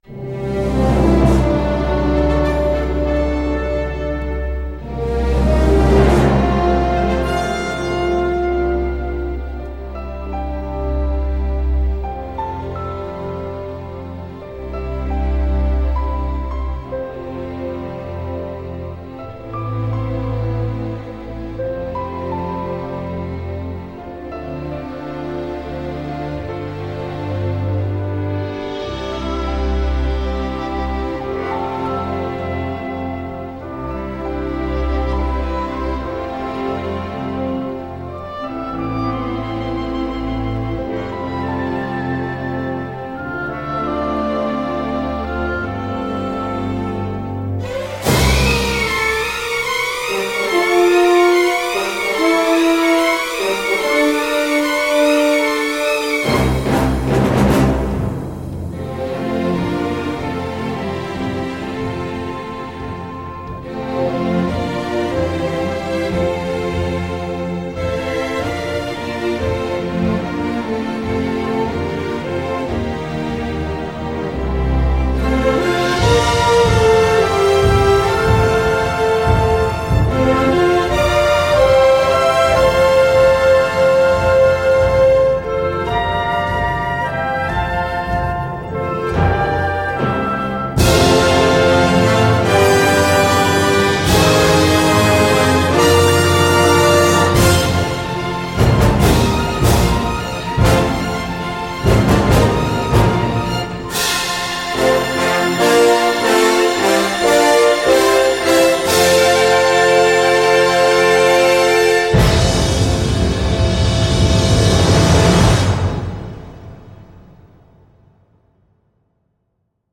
Finalement so eighties !